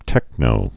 (tĕknō)